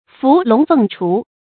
注音：ㄈㄨˊ ㄌㄨㄙˊ ㄈㄥˋ ㄔㄨˊ
伏龍鳳雛的讀法